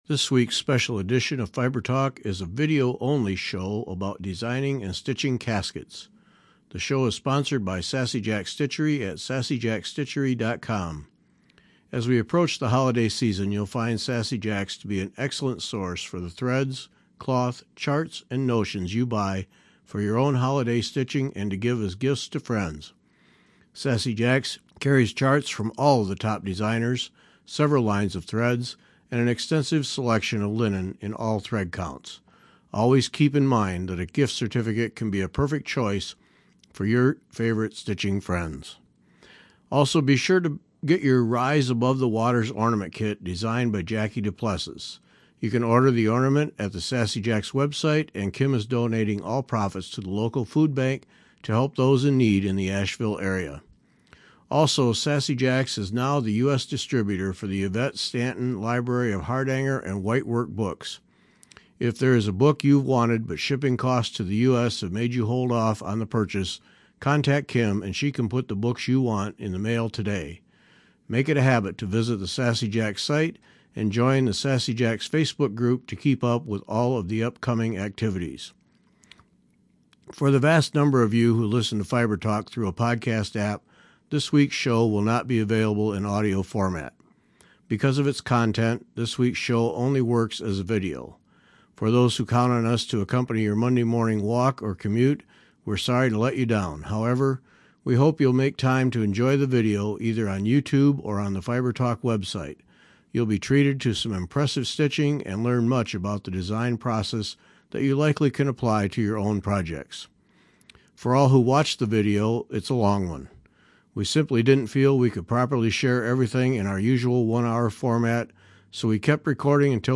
We cover a wide range of stitching topics that apply to needleworkers at all levels and hope we inspire you to tackle that challenging project you want to attempt but are not convinced you can handle. We hope you’ll take the time to enjoy our conversation and share it with your stitching friends.